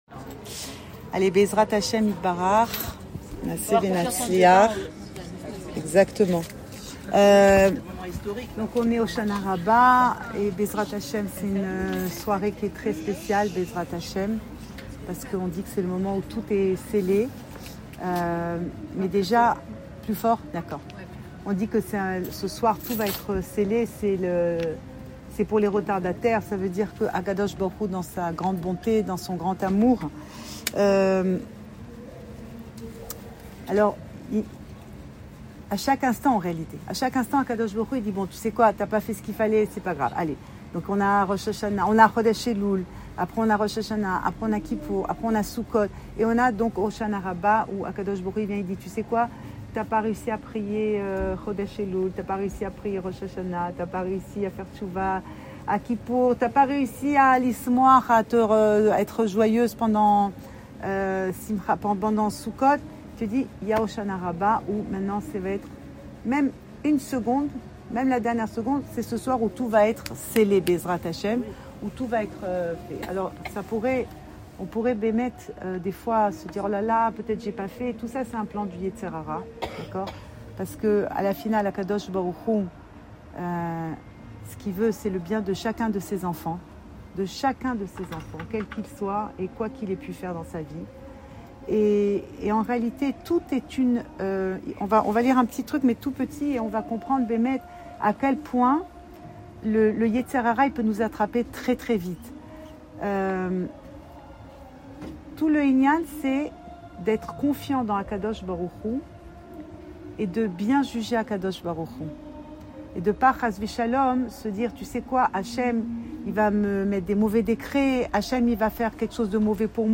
Cours audio
Enregistré à Jérusalem